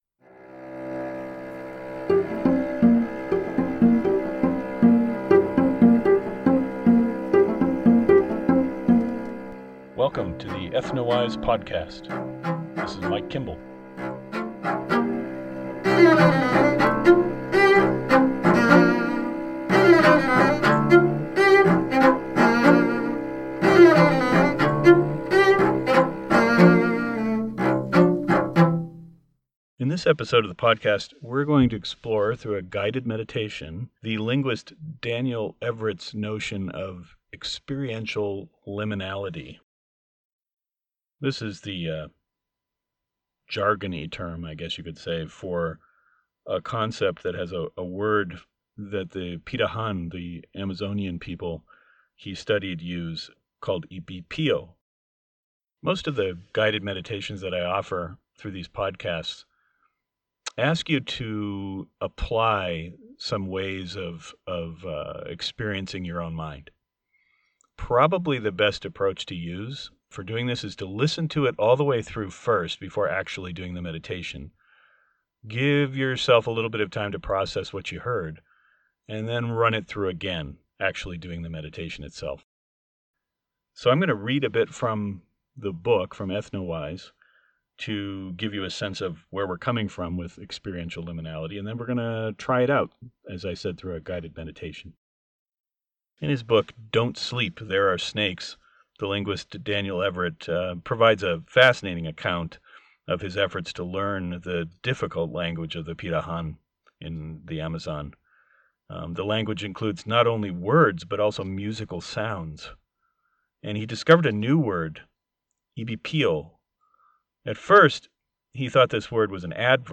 I present a guided meditation aimed at helping those of us who are not quite as anchored in the present moment as the Pirahã to taste experiential liminality.